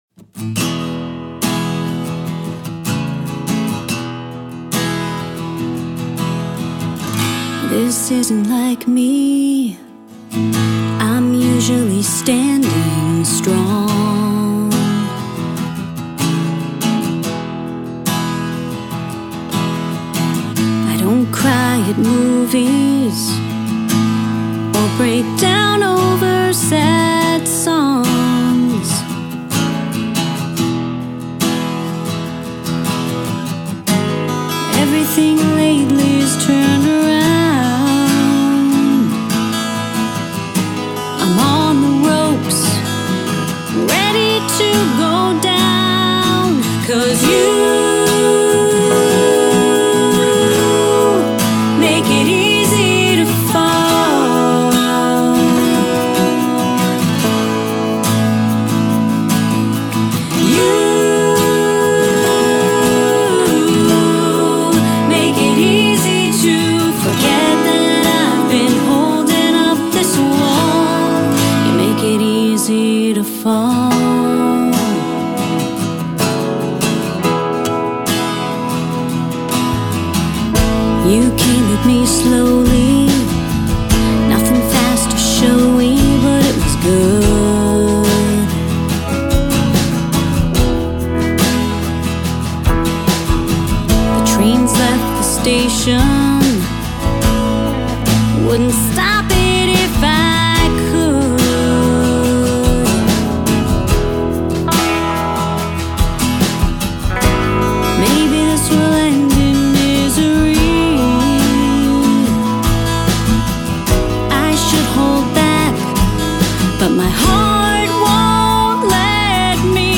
(country)